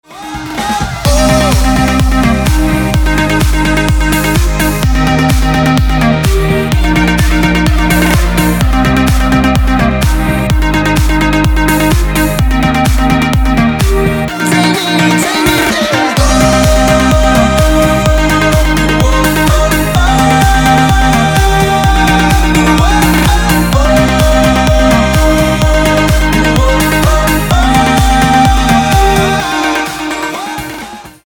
dance
Electronic
EDM
Club House
electro house